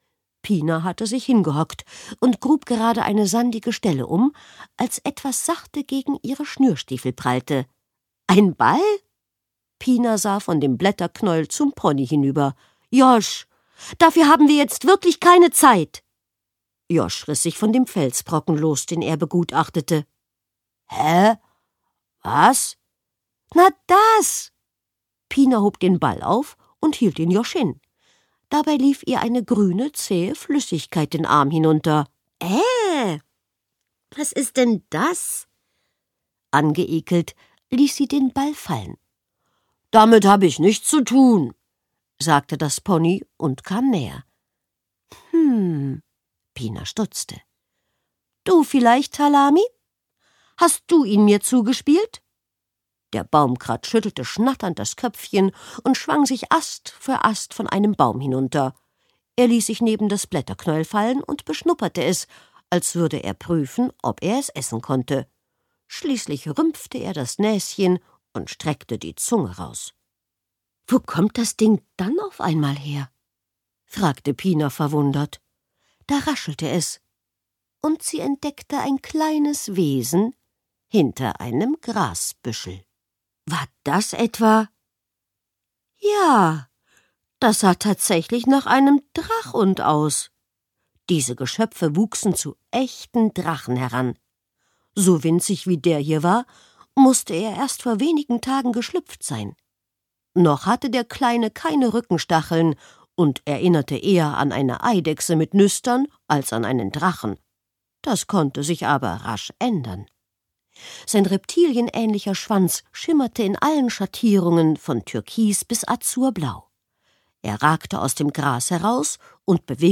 Eine magische Freundschaft: Zauberhafte Ponygeschichte mit einer frechen Hexe│Kinderhörbuch ab 6 Jahren
• Mit gereimten Zaubersprüchen und ganz viel Fantasie erzählt
Gekürzt Autorisierte, d.h. von Autor:innen und / oder Verlagen freigegebene, bearbeitete Fassung.